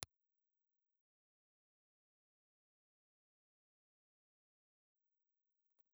Ribbon
Impulse Response file of National WM-702 in filter position 1
National_WM702_IR_HPF1.wav